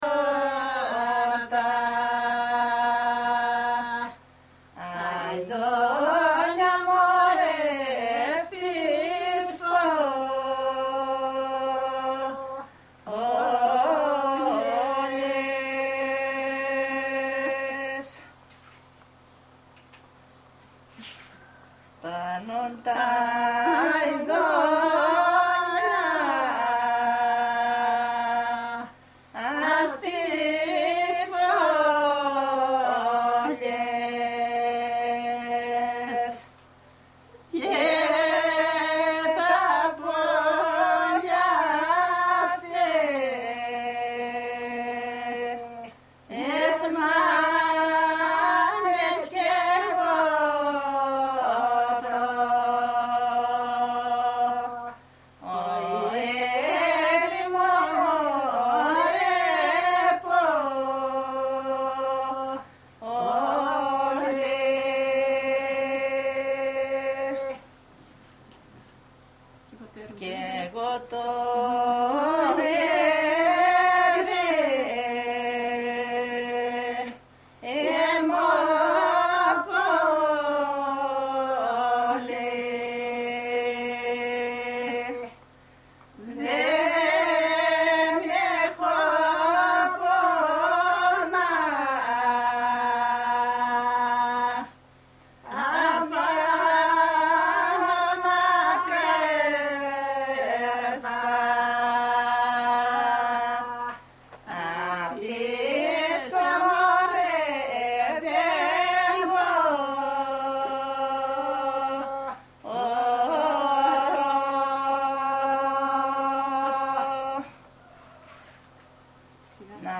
Έρευνα στα Μαστοροχώρια της Δυτικής Μακεδονίας, από το επιστημονικό προσωπικό του ΛΕΜΜ-Θ.
Συνέντευξεις με γυναίκες: οι γυναίκες τραγουδούν. (EL)
τραγούδια (EL)